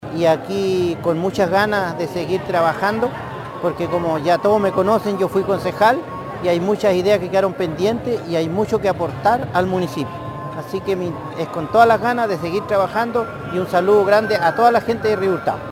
El concejal Solano De La Rivera manifestó sus ganas para seguir trabajando.